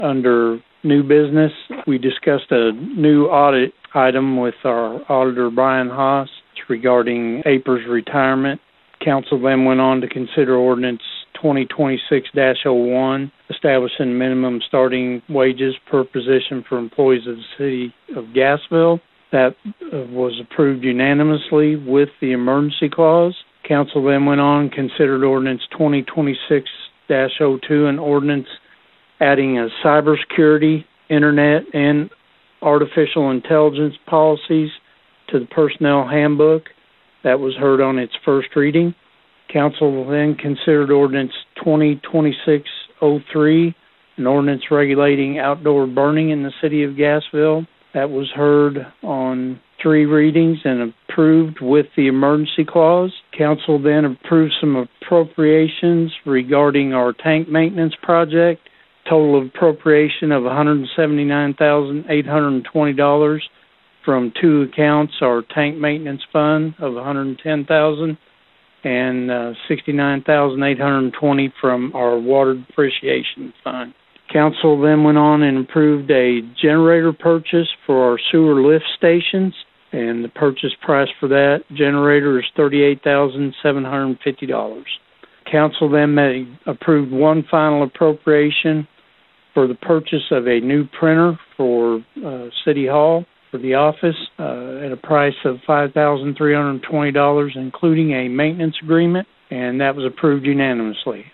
Mayor Jeff Braim shared the following recap with KTLO, Classic Hits and The Boot News.